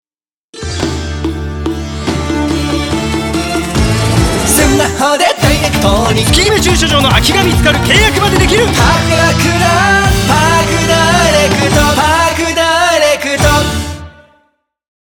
TVCM
SONG
TRIBAL
“駐ラクダ”というユニークなアイデアに寄り添い、サウンドは中東テイストを軸に構築しました。
異国感のある旋律やリズムを取り入れつつ、CM全体の軽さ・ユーモアが引き立つよう、ポップな質感で仕上げています。
一聴でキャッチできるフックと、遊び心を散りばめたアレンジを意識して制作しました。